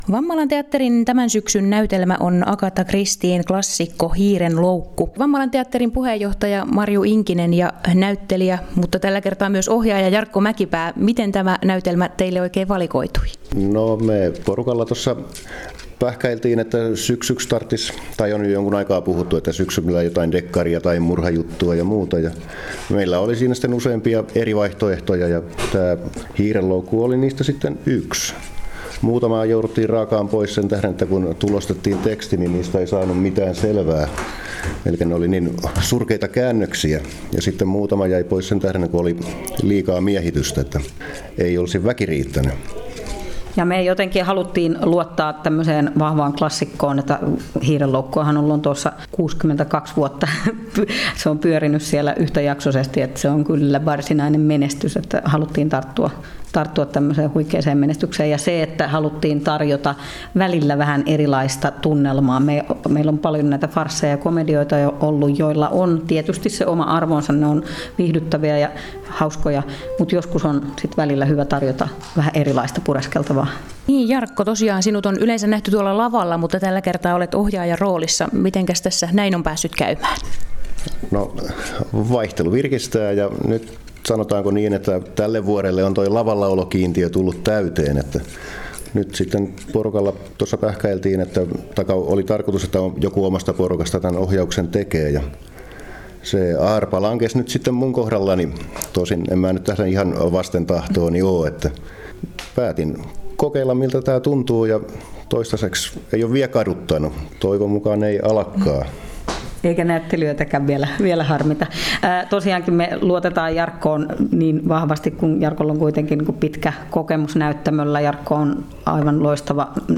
Vammalan-Teatteri-haastattelu.mp3